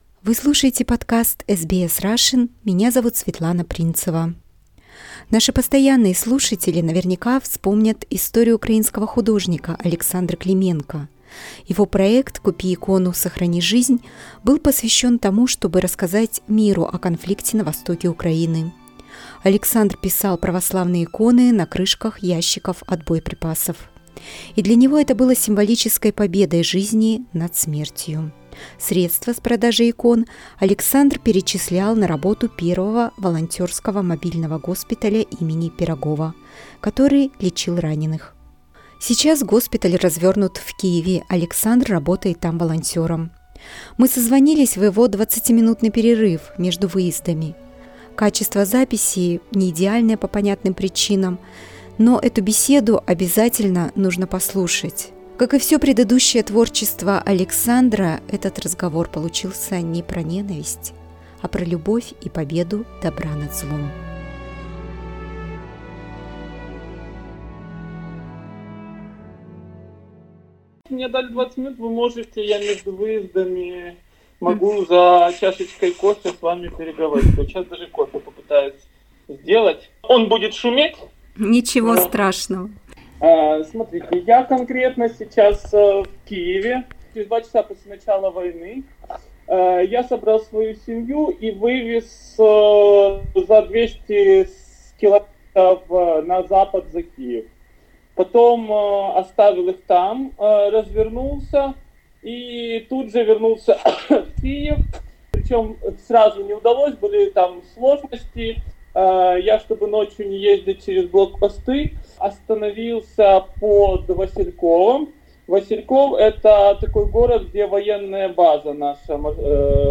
Мы созвонились в его 20-минутный перерыв между выездами. Качество записи неидеальное по понятным причинам.